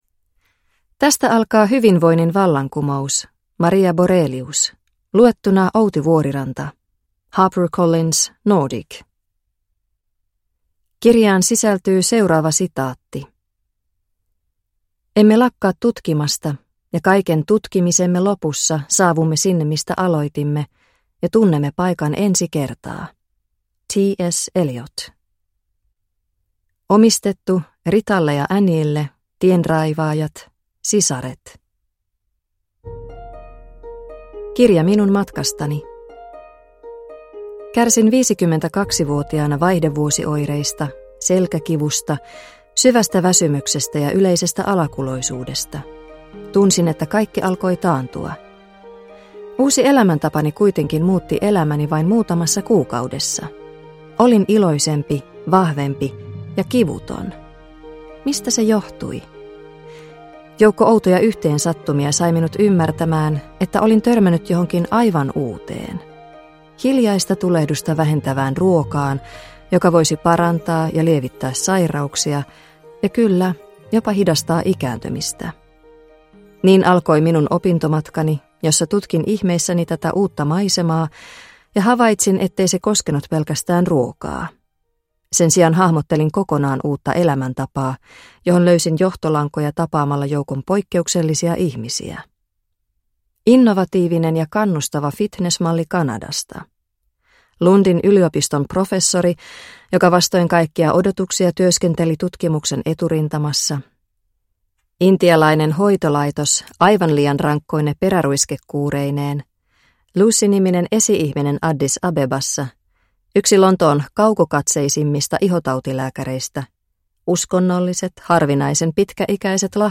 Hyvinvoinnin vallankumous – Ljudbok – Laddas ner